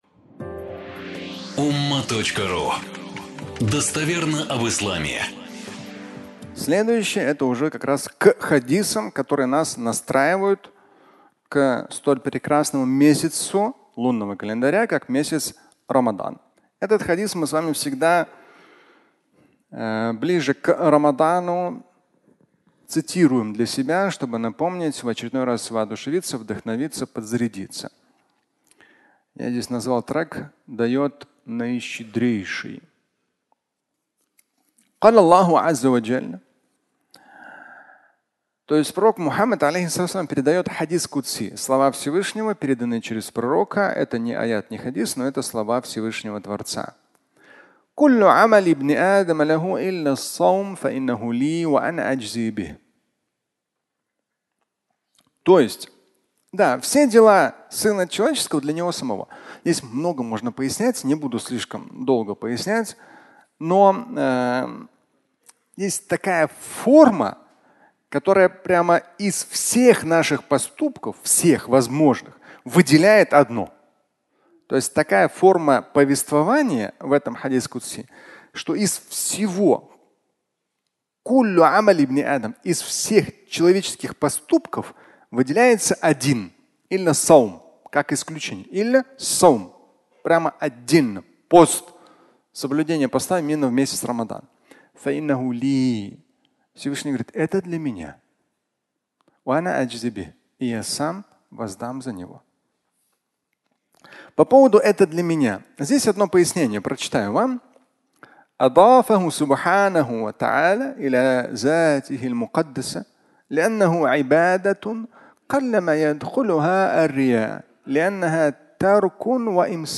Дает Наищедрейший (аудиолекция)
Фрагмент пятничной лекции, в котором Шамиль Аляутдинов говорит о ценности поста, цитируя хадис на эту тему.